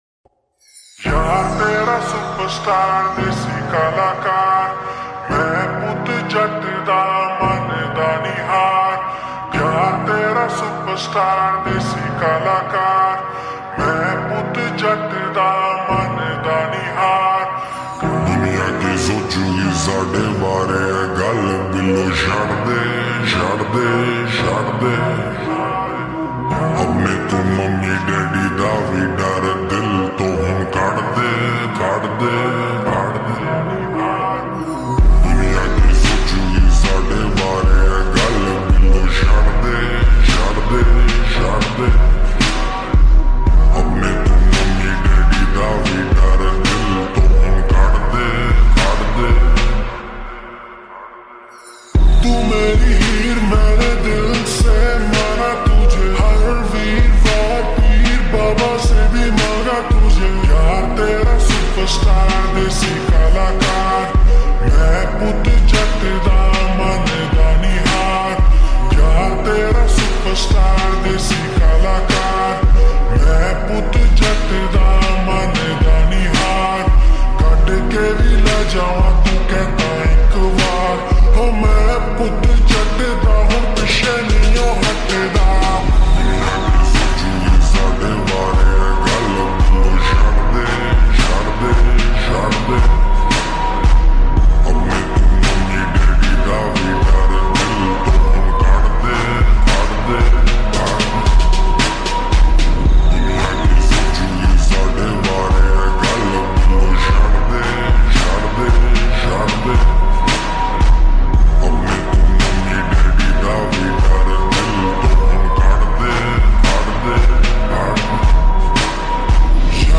𝕤𝕝𝕠𝕨𝕖𝕕 𝕣𝕖𝕧𝕖𝕣𝕓 𝕞𝕦𝕤𝕚𝕔